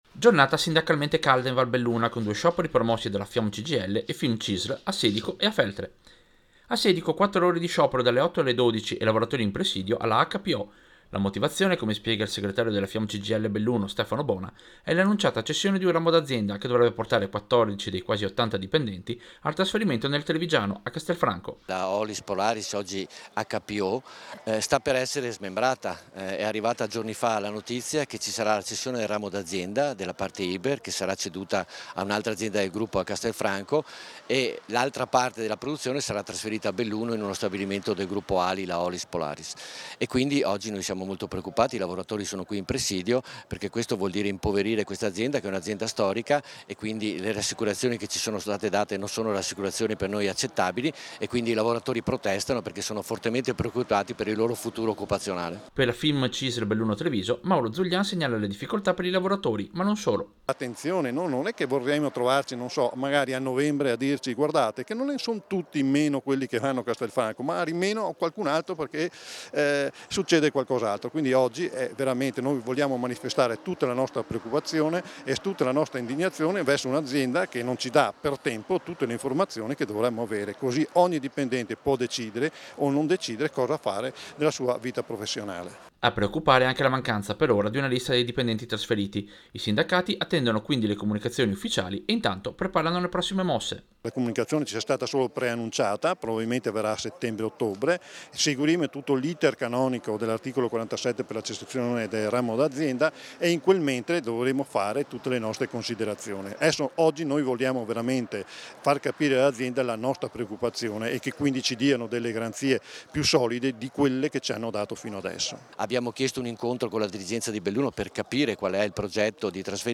Servizio-Presidio-Sedico-e-sciopero-Hydro.mp3